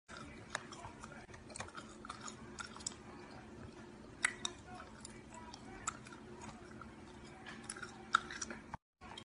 Crickets